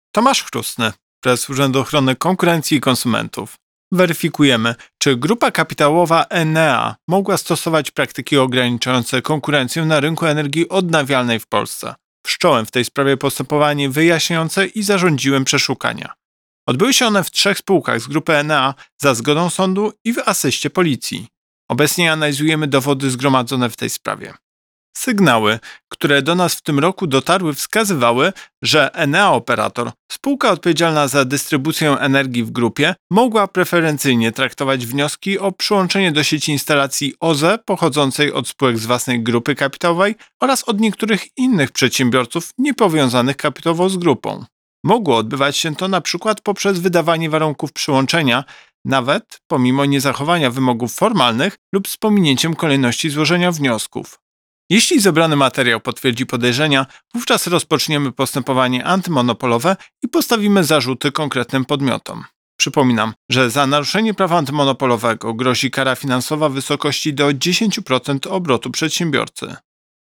Wypowiedź Prezesa UOKiK Tomasza Chróstnego z 2 grudnia 2024 r..mp3